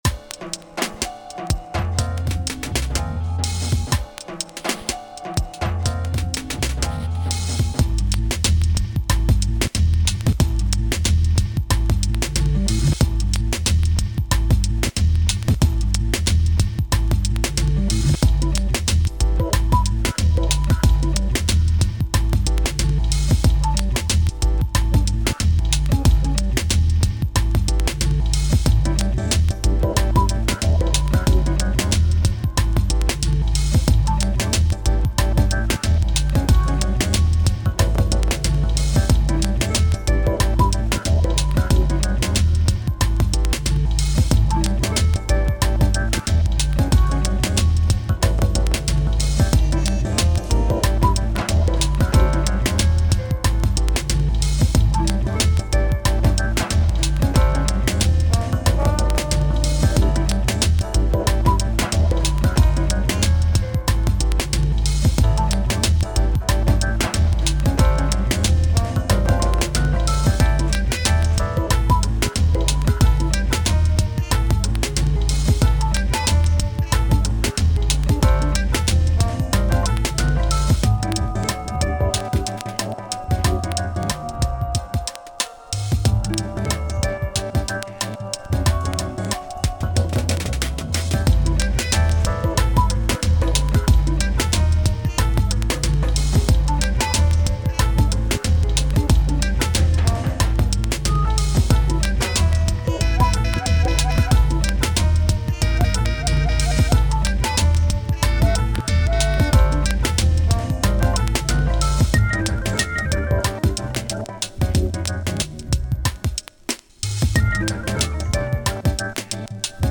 That is a funky track, and love the different directions you took the song structure.
I like the bit where it sounds like a jazz man is clicking their fingers, some great uses of the sample in here!
Also I forgot to mention kick/hat/snare~a few drum samples were added everything else was from the original track